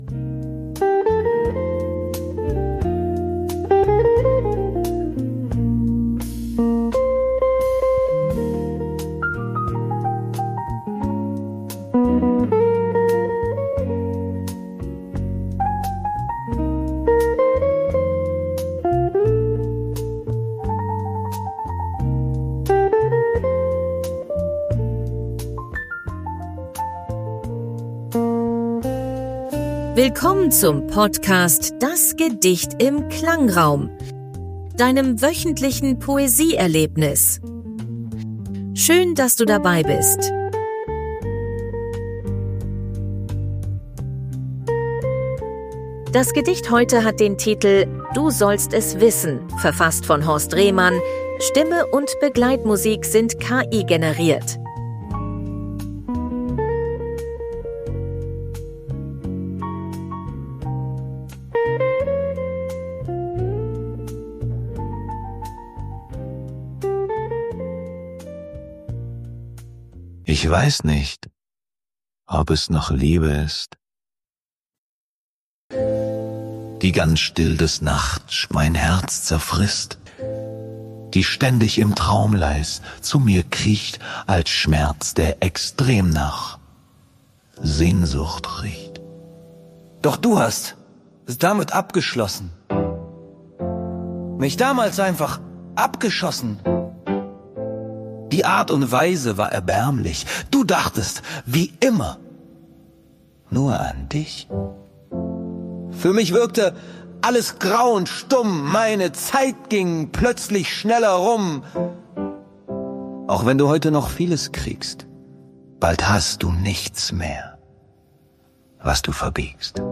Begleitmusik sind KI generiert.